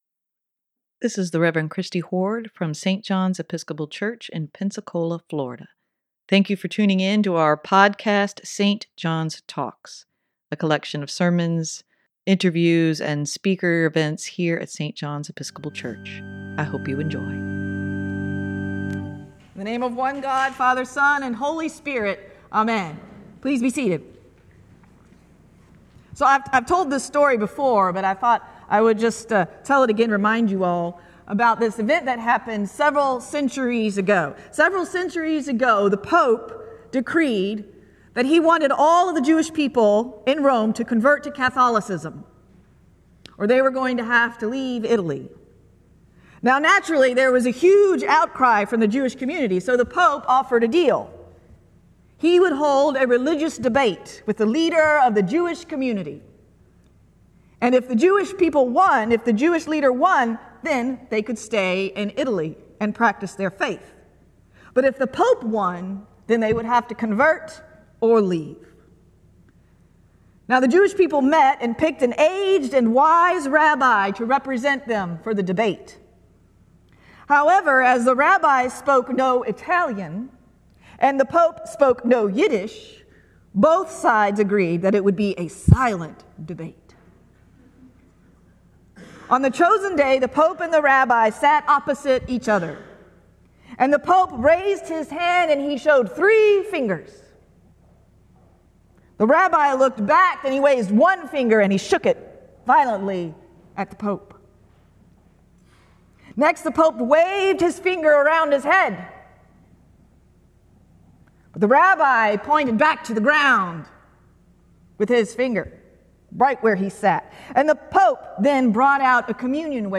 Sermon for Trinity Sunday, May 26, 2024: God is right here with us - St. John's Episcopal Church, Pensacola, Florida